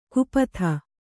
♪ kupatha